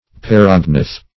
paragnath - definition of paragnath - synonyms, pronunciation, spelling from Free Dictionary Search Result for " paragnath" : The Collaborative International Dictionary of English v.0.48: Paragnath \Par"ag*nath\ (p[a^]r"[a^]g*n[a^]th), n. (Zool.)